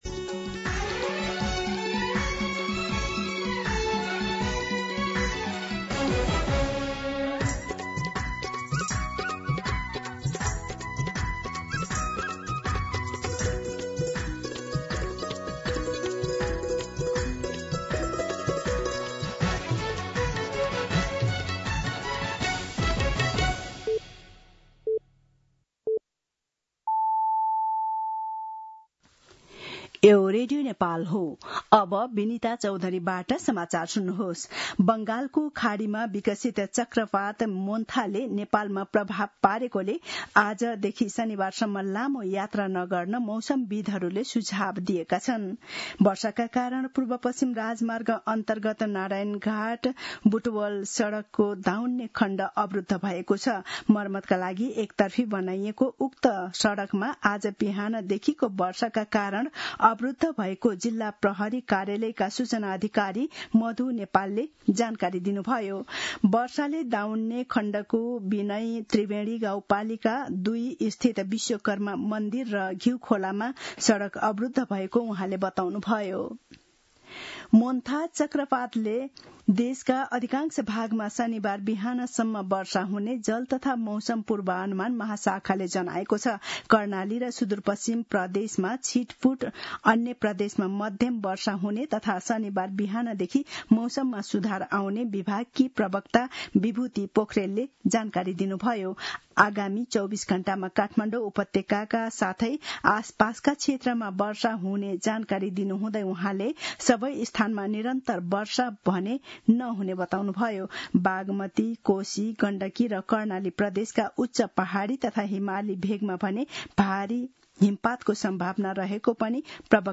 मध्यान्ह १२ बजेको नेपाली समाचार : १३ कार्तिक , २०८२
12-pm-Nepali-news.mp3